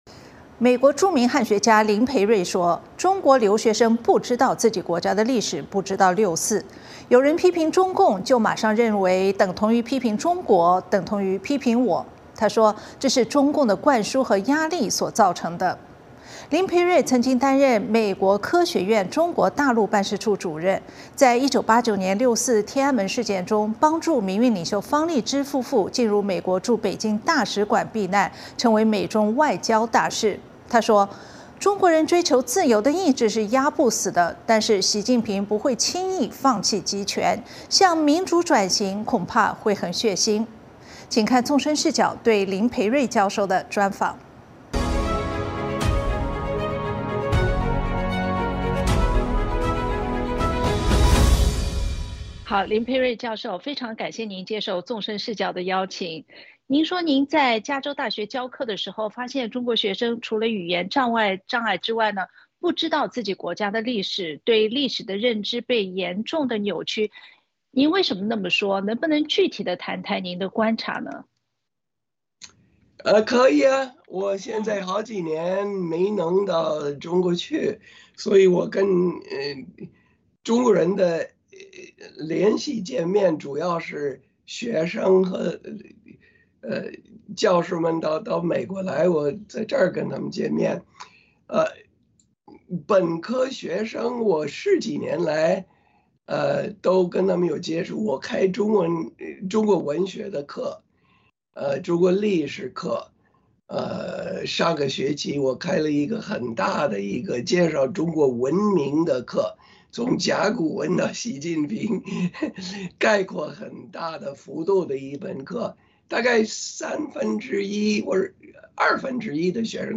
《纵深视角》节目进行一系列人物专访，受访者所发表的评论不代表美国之音的立场。